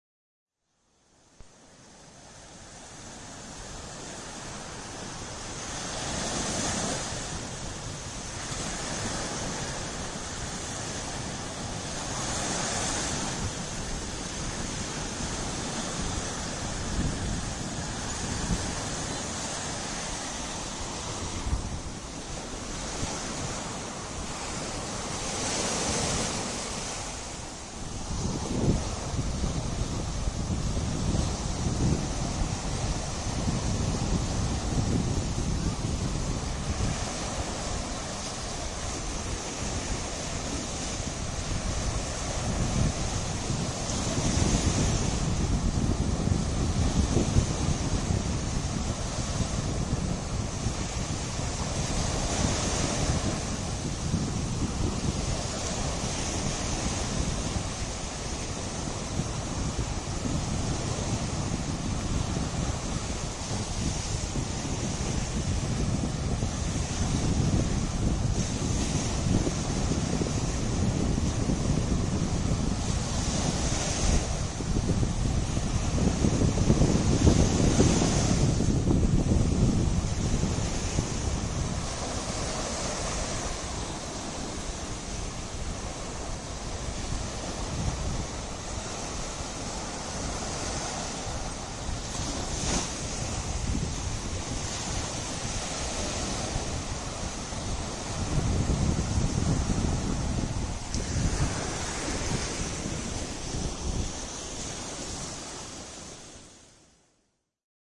普拉亚德卡门的海浪冲上了海滩
描述：在墨西哥普拉亚德尔卡门撞击海岸线的风和海浪的声音。